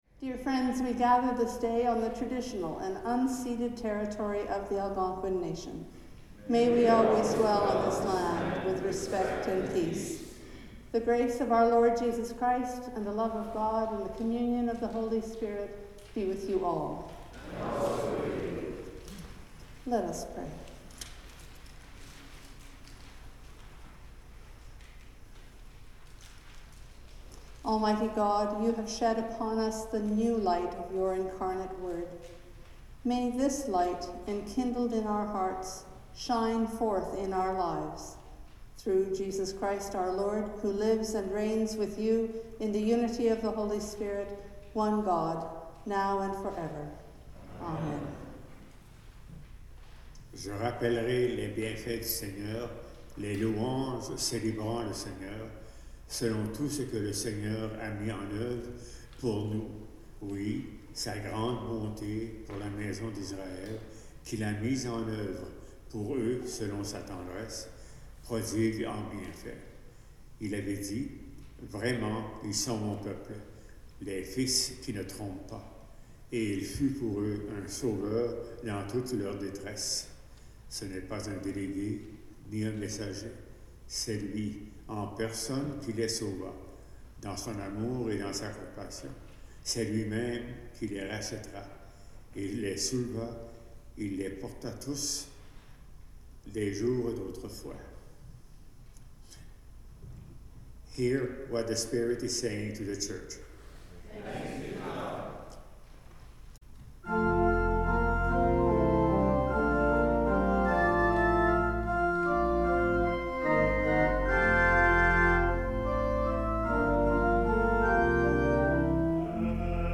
Sermons | St John the Evangelist